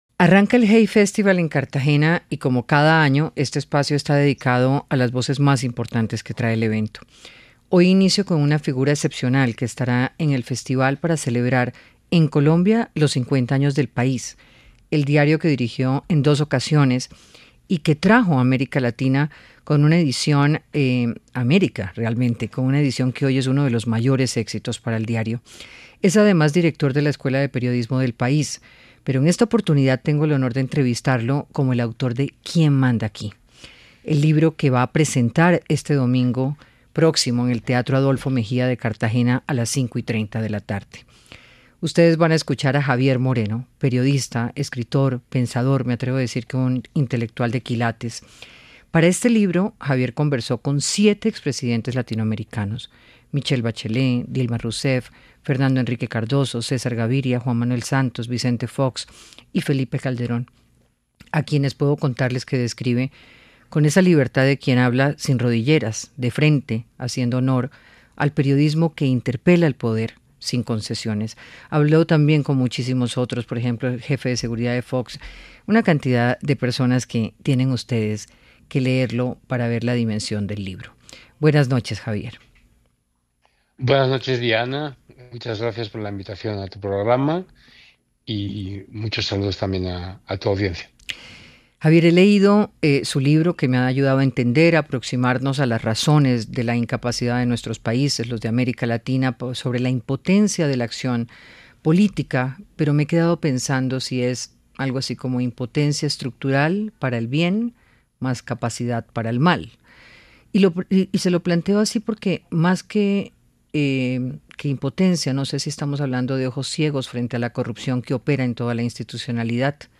Desde el Hay Festival en Cartagena el dos veces director de El País habla con Diana Calderón del poder, la seguridad y la corrupción en América Latina.